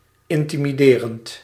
Ääntäminen
IPA: [kɔ.lɔ.sal]